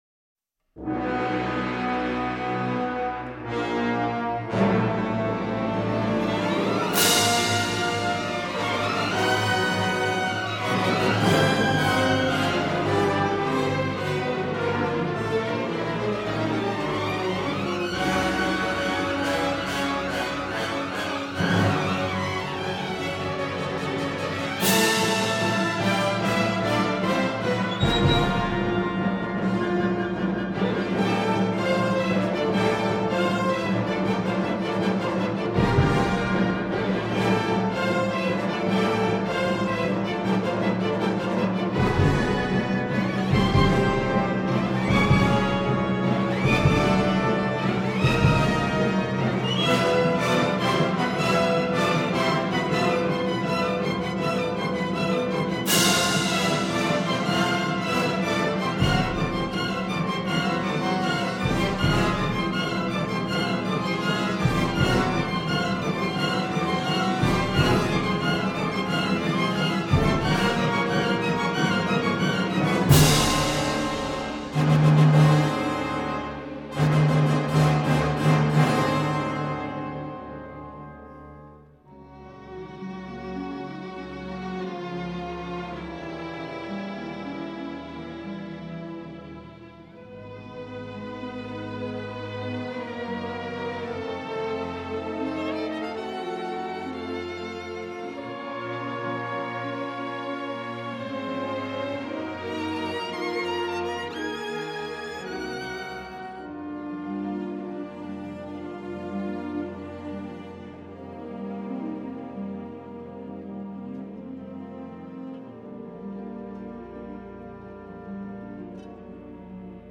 Les cordes, en particulier, manquent d’épaisseur.